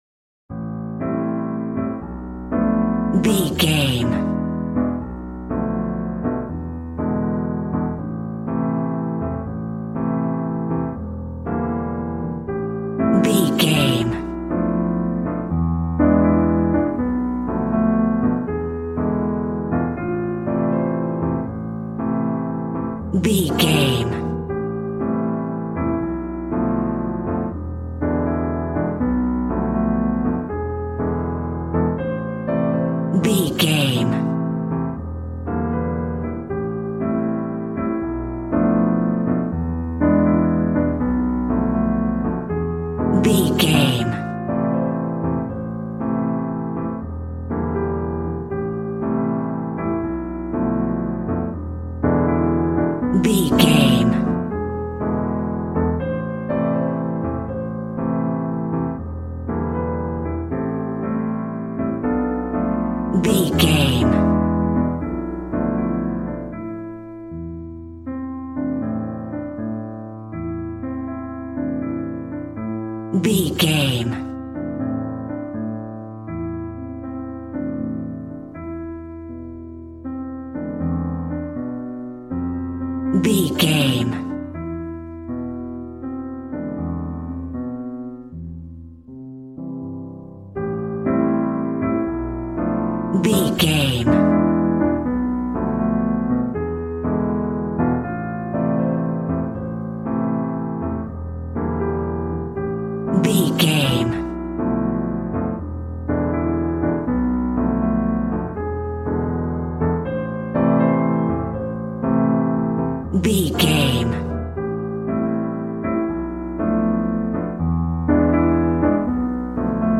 Aeolian/Minor
smooth
piano
drums